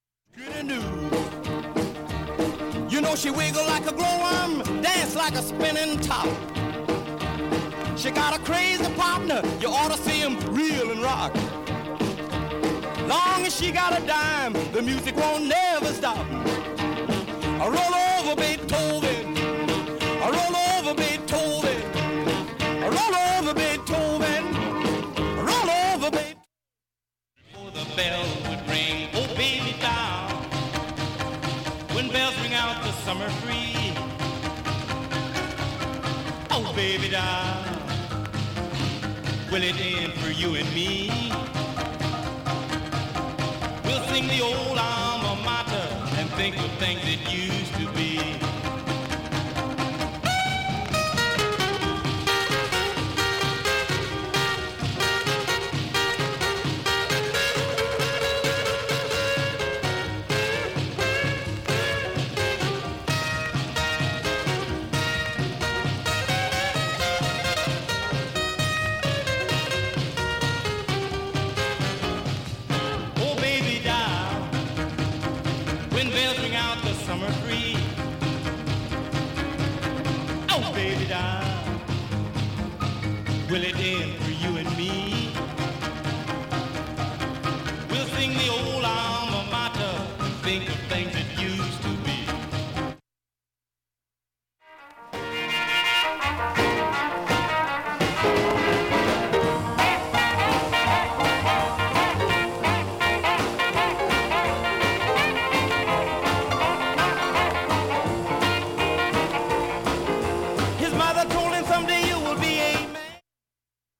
B-5後半にかすかなプツが10回出ます 現物の試聴（上記録音時間２分）できます。